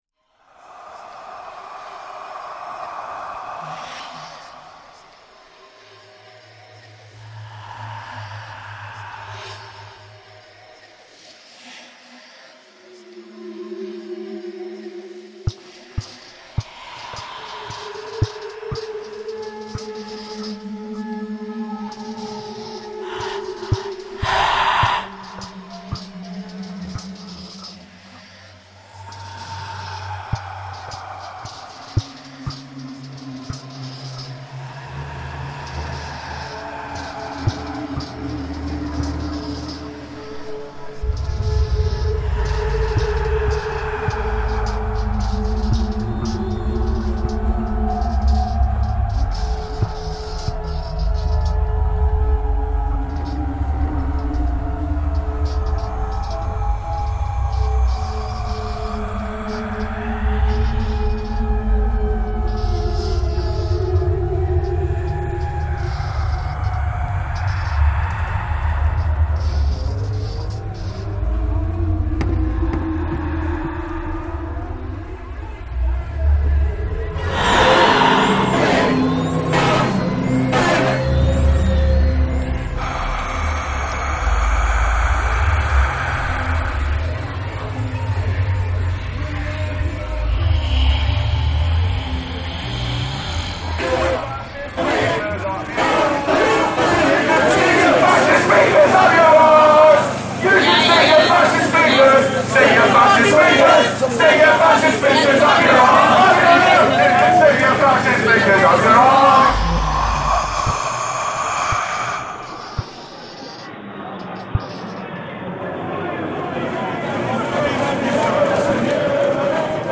Oxford anti-Le Pen protest reimagined